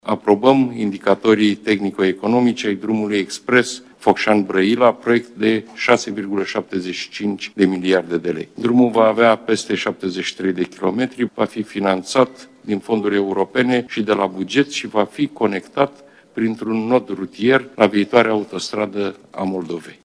„Aprobăm indicatorii tehnico-economici ai drumului expres Focșani – Brăila, proiect de 6, 75 de miliarde de lei. Drumul va avea peste 73 de kilometri, va fi finanțat prin fonduri europene și de la buget și va fi conectat printr-un nod rutier la viitoarea Autostradă a Moldovei”, a declarat joi Marcel Ciolacu.